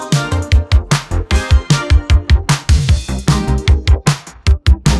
"80s R&B"
"genre": "80s R&B",